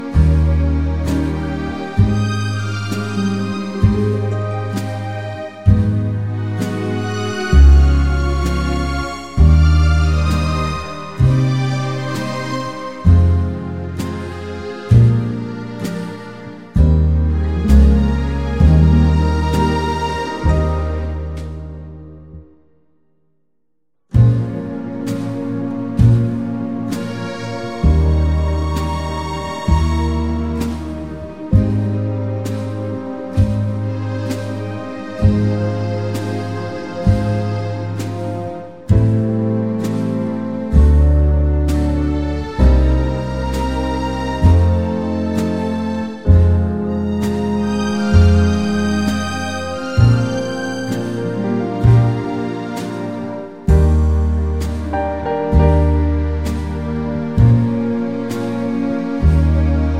Professional Easy Listening Backing Tracks.